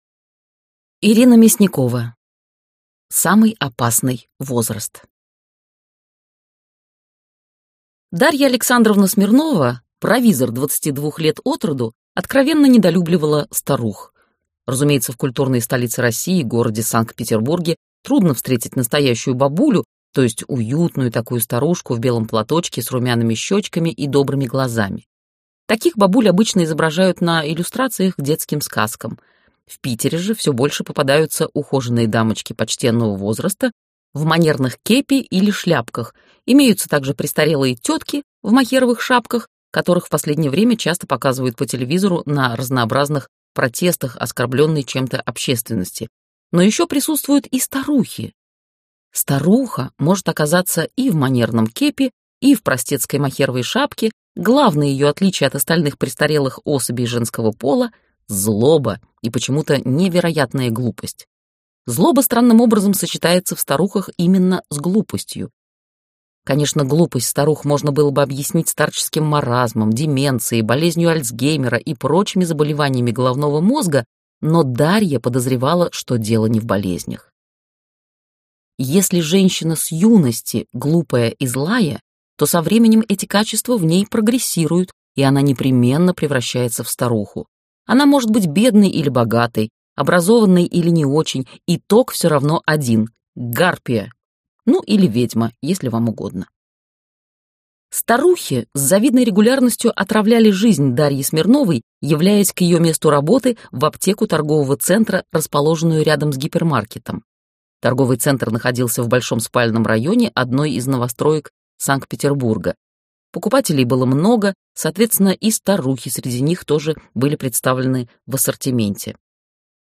Аудиокнига Самый опасный возраст | Библиотека аудиокниг